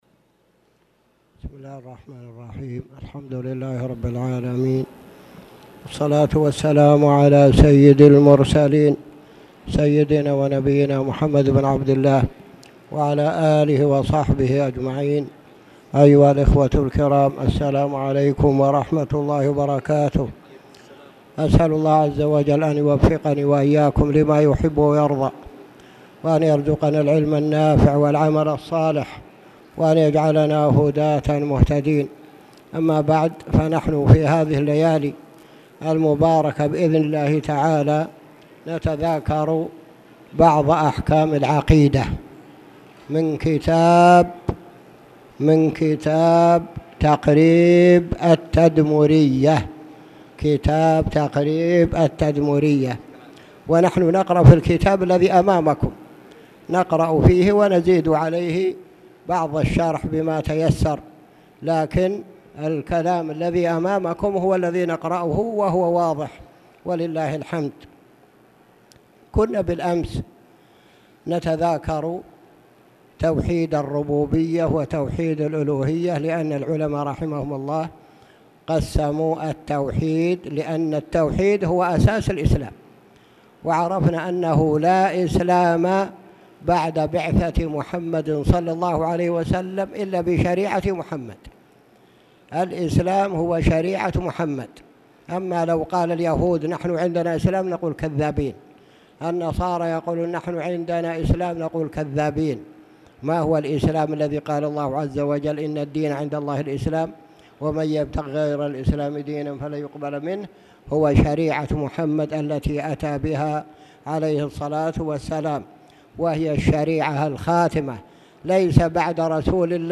تاريخ النشر ٢٠ صفر ١٤٣٨ هـ المكان: المسجد الحرام الشيخ